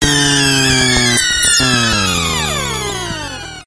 Descarga de Sonidos mp3 Gratis: muerte robot.
dying-robot.mp3